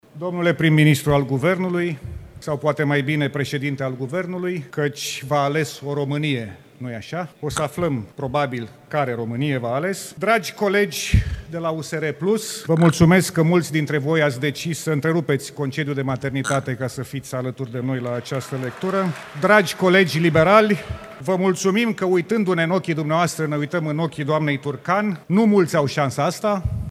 În Parlament, senatorii și deputații s-au reunit pentru a dezbate și vota moțiunea de cenzură inițiată de PSD împotriva guvernului condus de Florin Cîțu. Documentul este citit de social democratul Lucian Romașcanu, care a început cu ironii la adresa premierului dar si a parlamentarilor din PNL și USR PLUS.